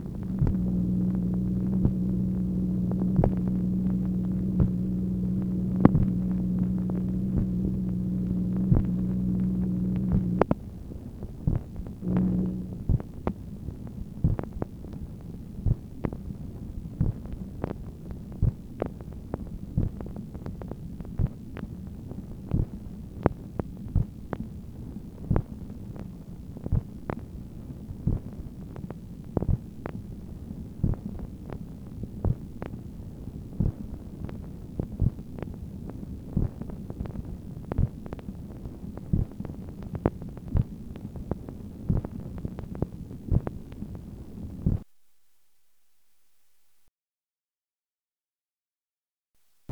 MACHINE NOISE, January 15, 1964
Secret White House Tapes | Lyndon B. Johnson Presidency